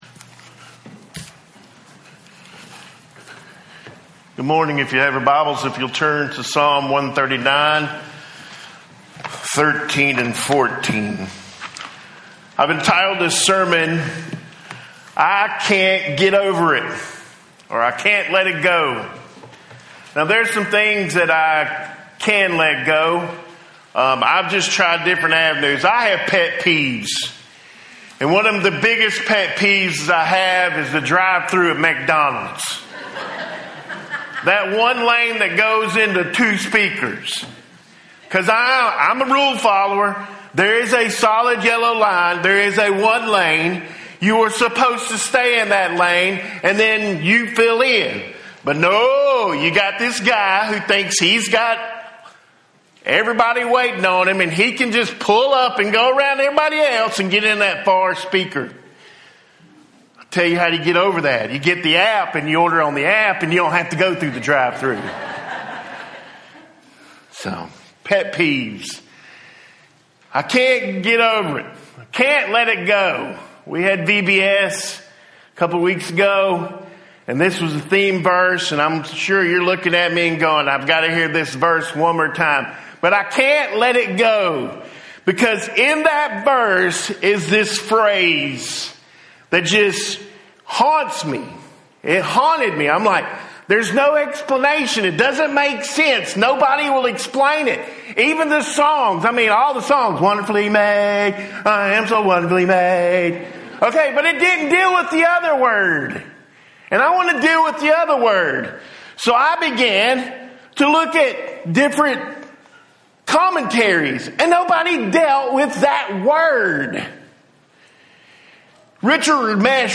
Fearfully and Wonderfully Made Audio Sermon